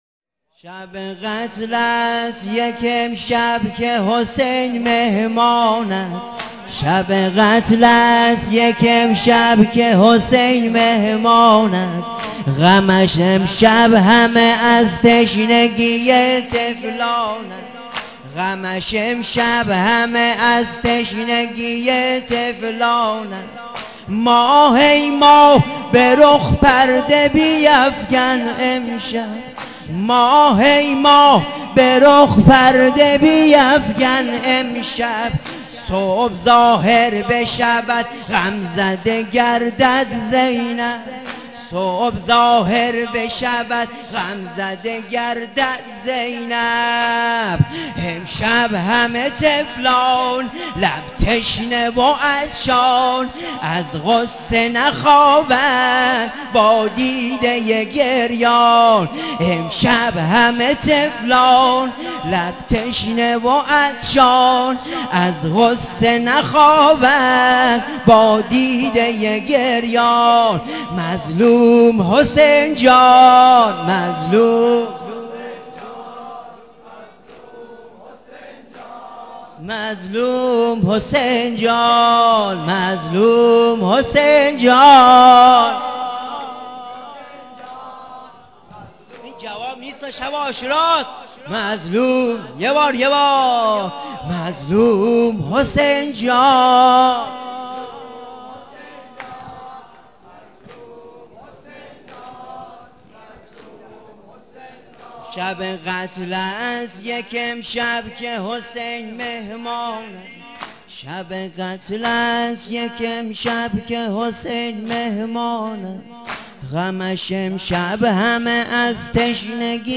نوحه سینه زنی شب عاشورامحرم ۹۷